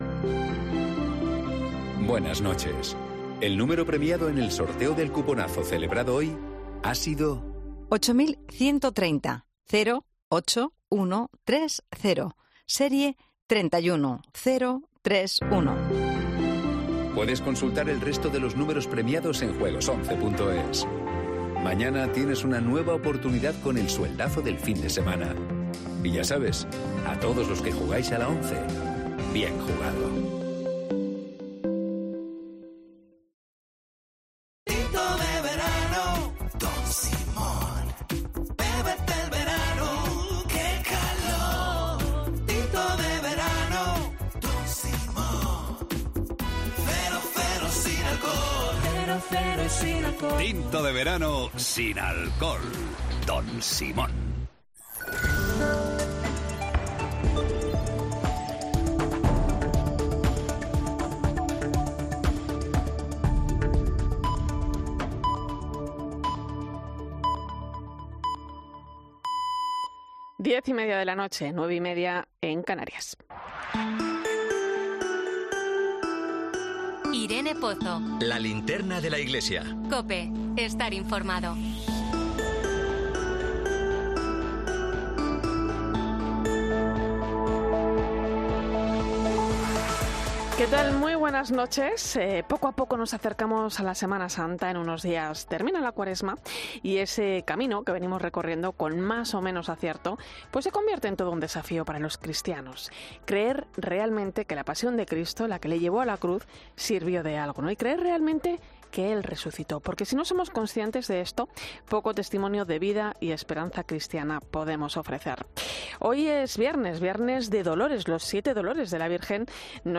El monólogo